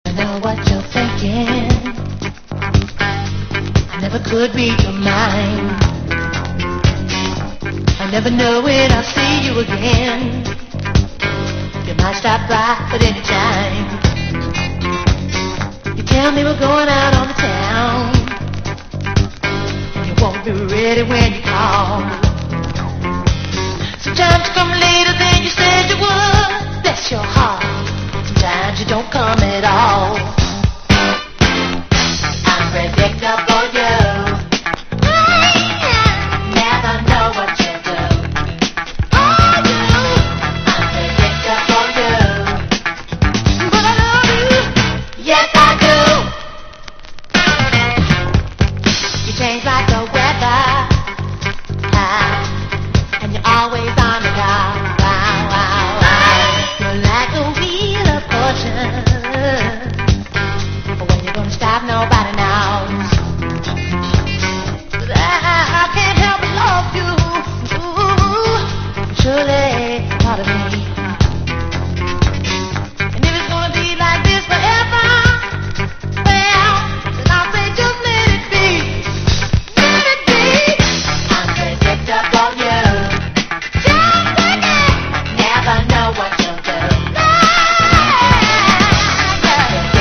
フィリーディスコなA1やファンクなA5もありますが、全体的に落ち着いた雰囲気の1枚。